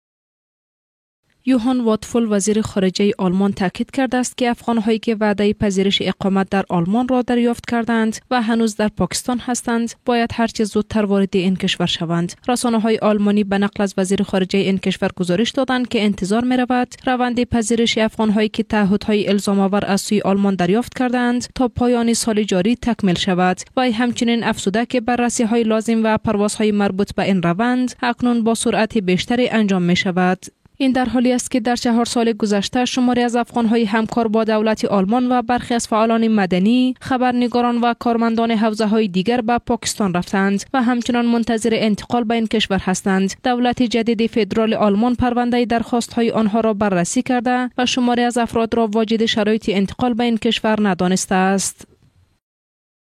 Voice Over Artistes- DARI
Leading Female Voice over in DARI language.